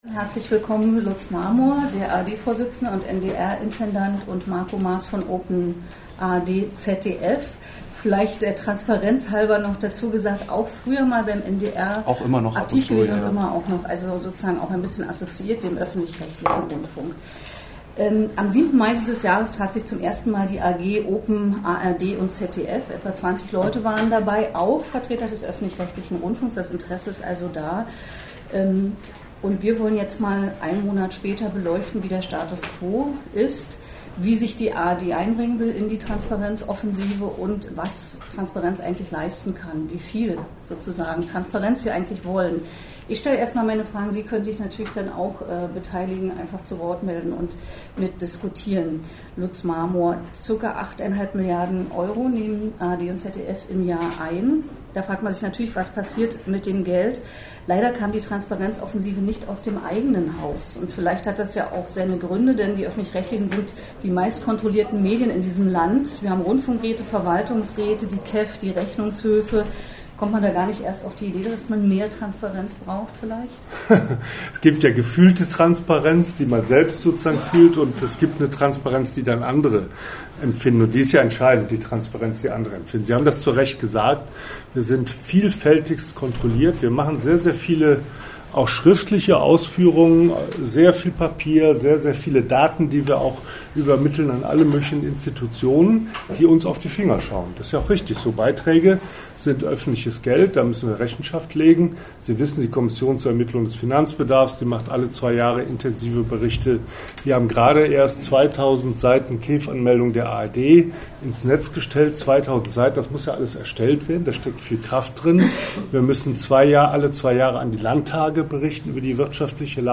– Ein Streitgespräch zu den Öffentlich-Rechtlichen, Jahrestreffen netzwerk recherche Wo: Hamburg, NDR-Fernsehzentrum Wann: 15.06.2013, 10:45 Uhr Wer: (v.l.n.r.)
Ein Streitgespräch zu den Öffentlich-Rechtlichen No audio playback capabilities, please download the audio below Download Audio: Closed Format: MP3 Was: Transparenz!?